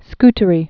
(sktə-rē) also Ska·dar (skädär), Lake